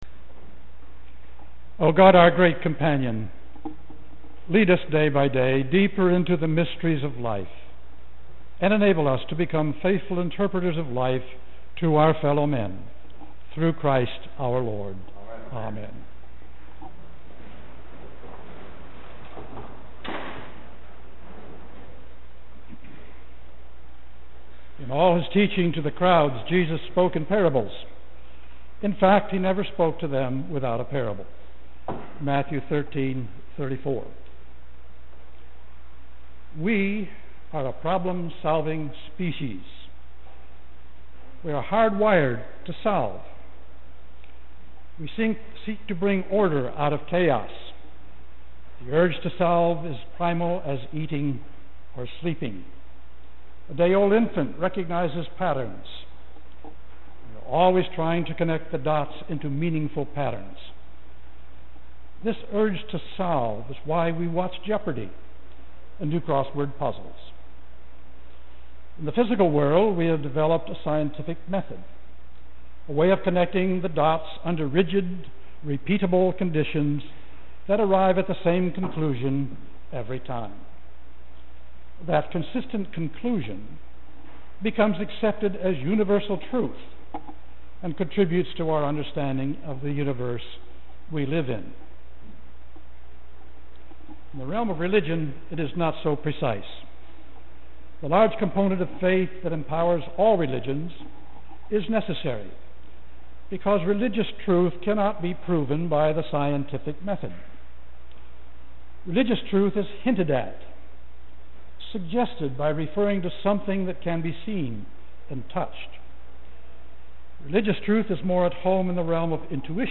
Sermon July 27